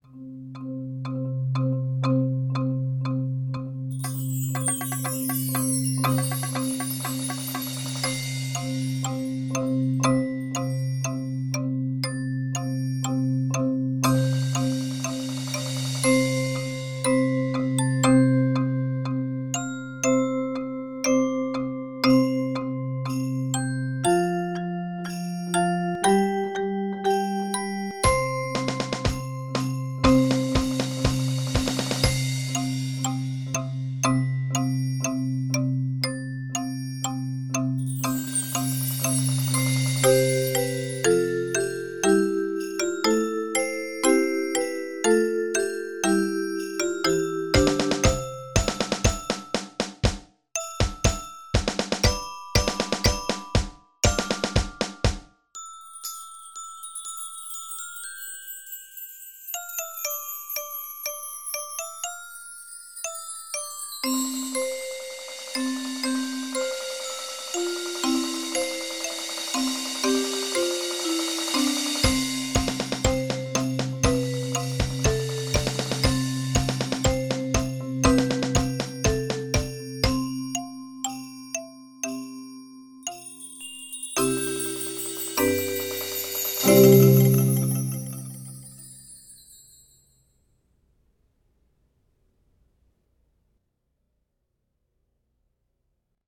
Voicing: 12-20 Players